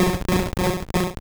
Cri de Krabby dans Pokémon Or et Argent.